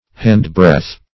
Handbreadth \Hand"breadth`\ (-br[e^]dth`), n.